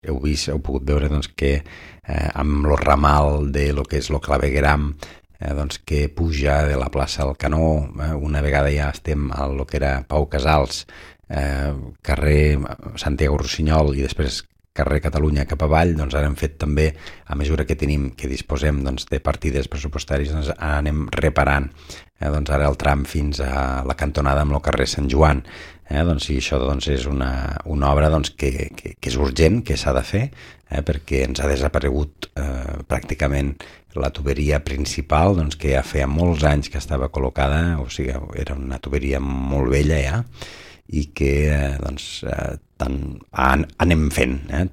Jordi Gaseni és l’alcalde de l’Ametlla de Mar: